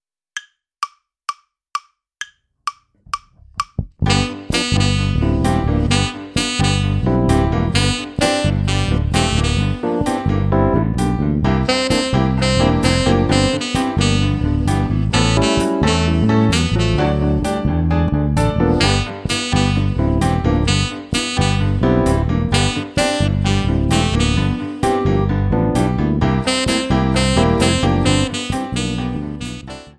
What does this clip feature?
Besetzung: Schlagzeug Drumset Playalongs